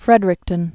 (frĕdrĭk-tən, -ər-ĭk-)